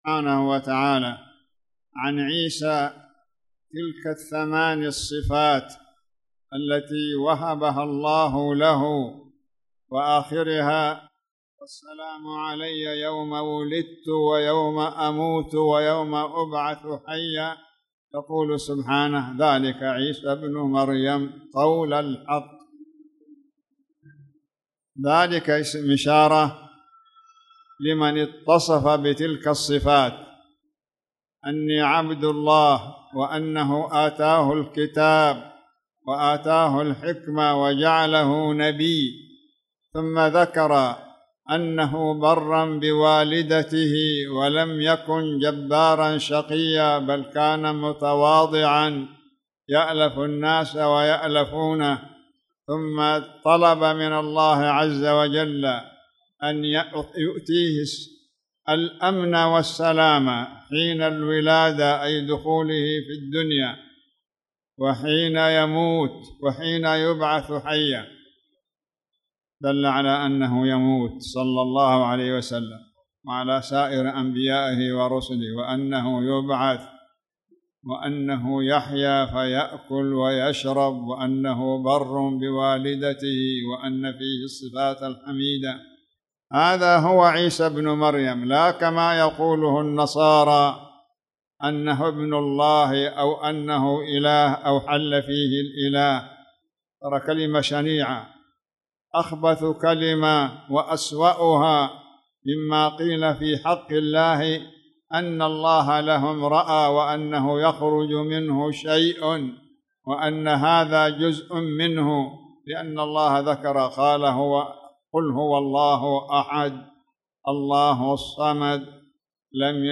تاريخ النشر ٤ ربيع الثاني ١٤٣٨ هـ المكان: المسجد الحرام الشيخ